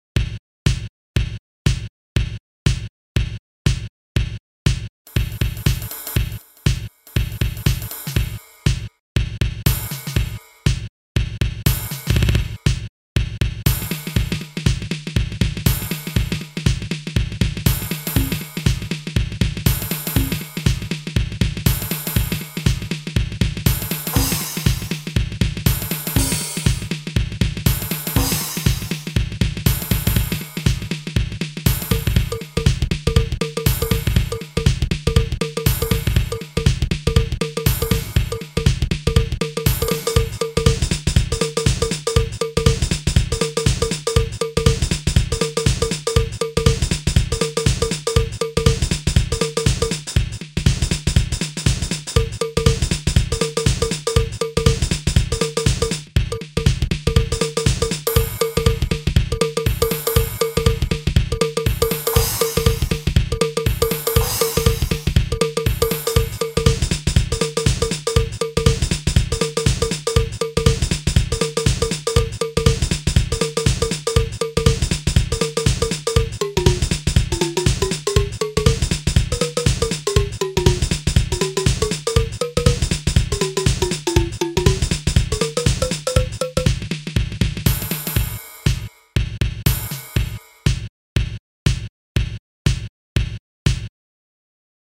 edit WAVETABLE internal ROM contains 23 PCM samples onboard recorded at 12 bits, these sounds are mainly classic acoustic percussion.
drum session
Synthesis: PCM rompler